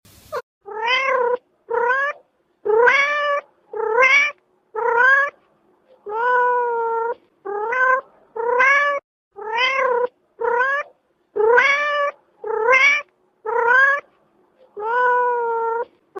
😺😺🐈Kitten Meowing Sound to Attract sound effects free download